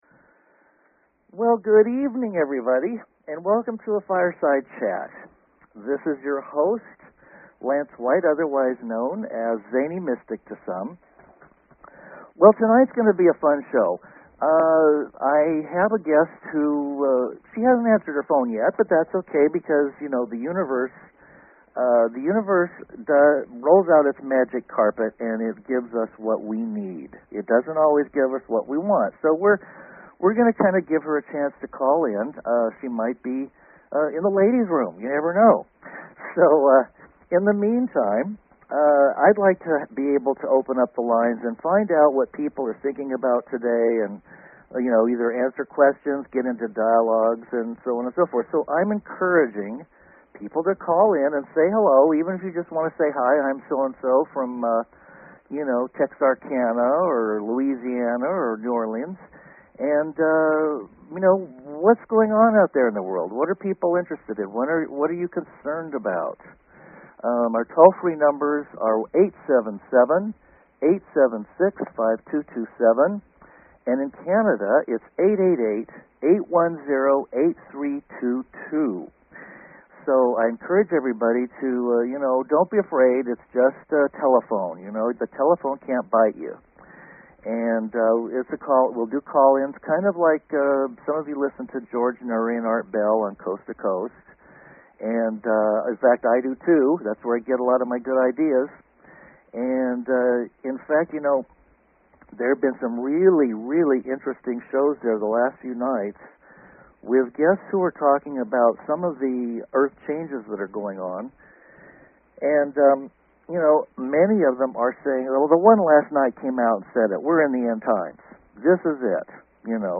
Live, Call In Show!